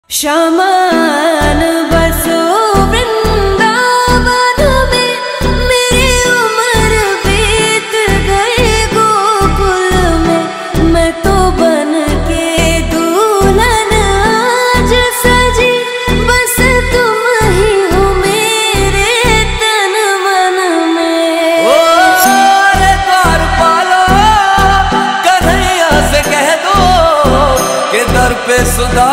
• Simple and Lofi sound
• Crisp and clear sound